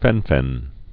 (fĕnfĕn)